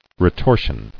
[re·tor·sion]